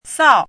chinese-voice - 汉字语音库
sao4.mp3